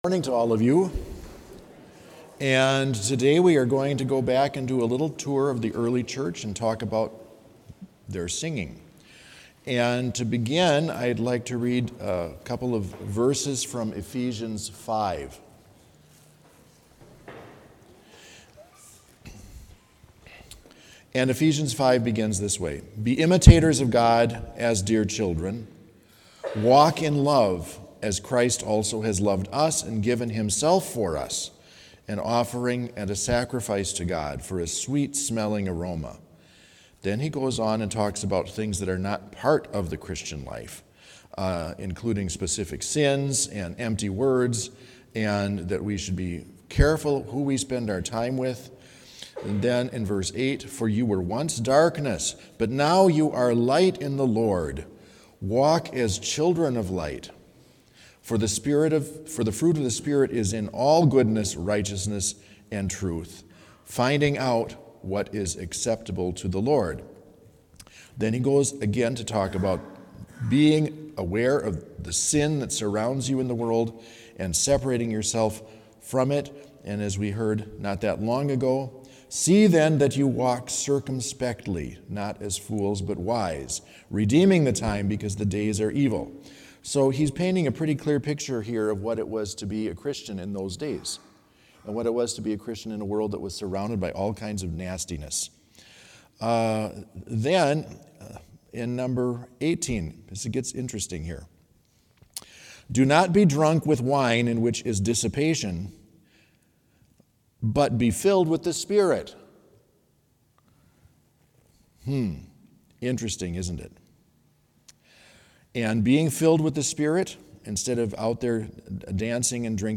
Complete service audio for Chapel - October 19, 2022